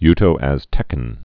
(ytō-ăztĕkən)